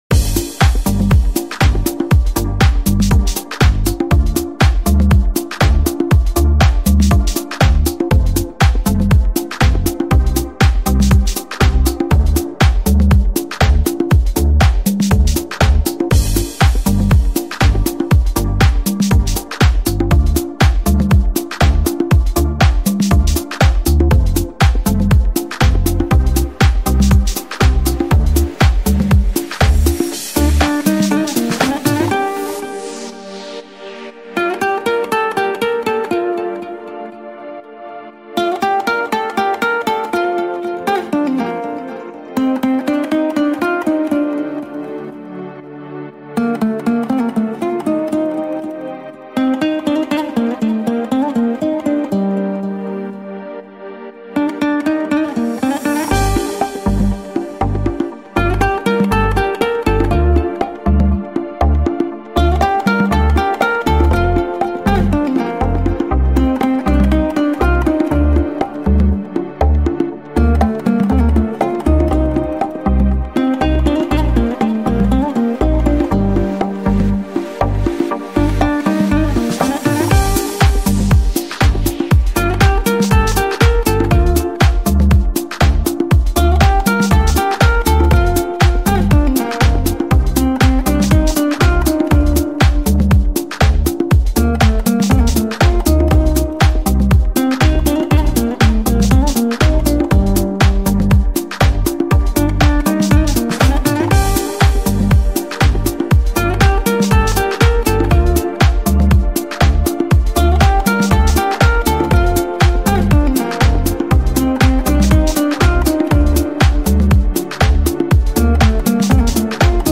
яркий пример жанра электронной музыки с элементами хип-хопа